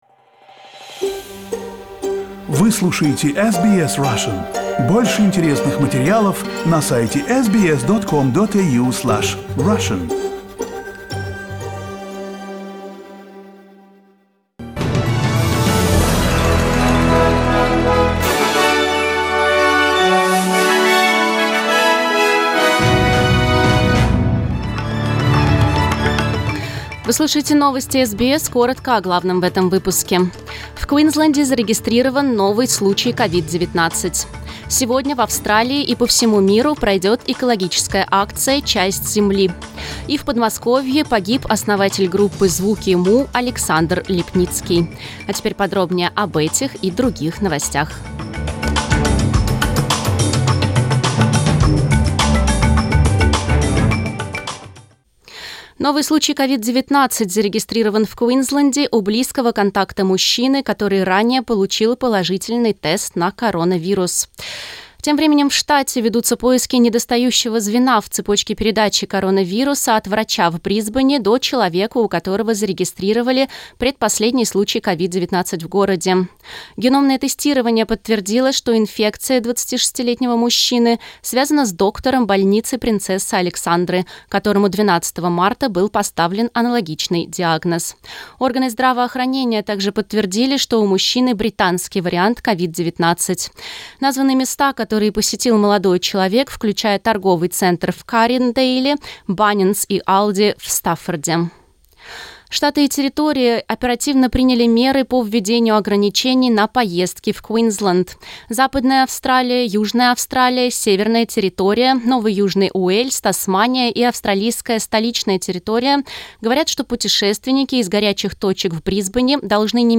Новостной выпуск за 27 марта